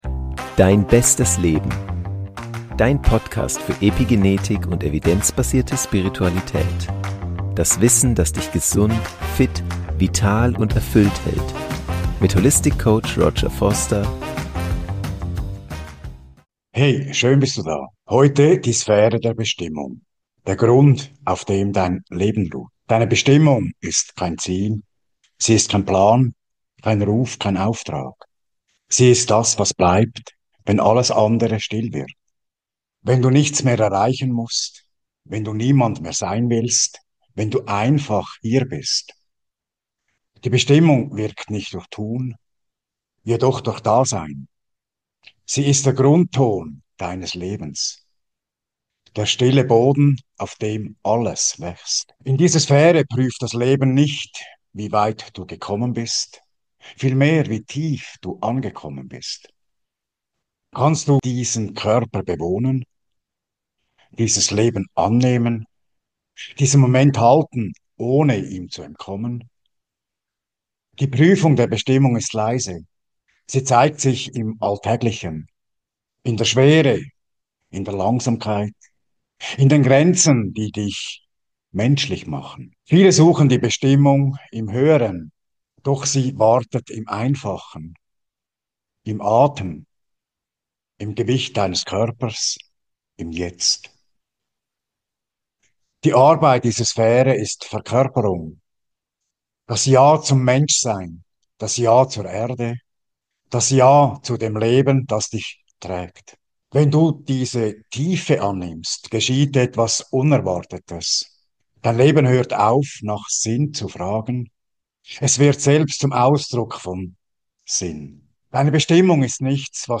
Nach einem kurzen Intro tauchen wir gemeinsam in eine kontemplative Meditation ein, getragen von Musik und Stille. Diese Reise führt Dich aus dem Denken zurück in den Körper, aus dem Suchen in das Ankommen.
Empfohlen mit Kopfhörern und ein paar ungestörten Minuten.